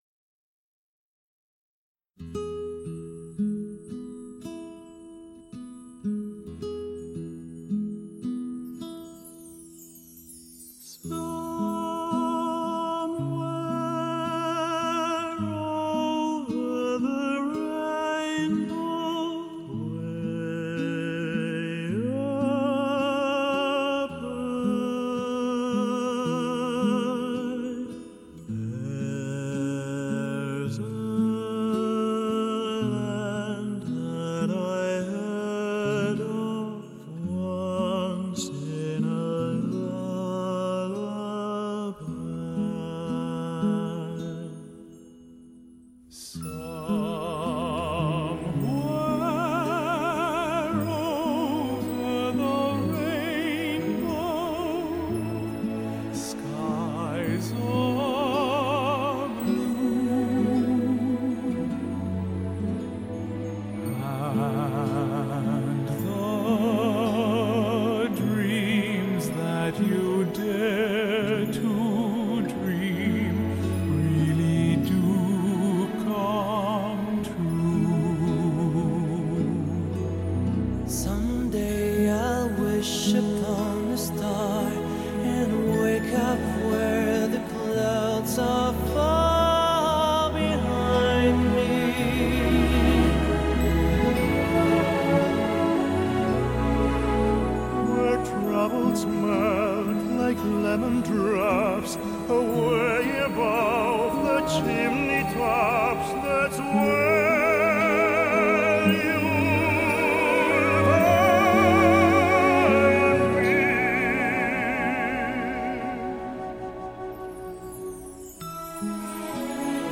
歌喉为此歌带来了安详宁静的感觉，令人情不自禁地沉醉……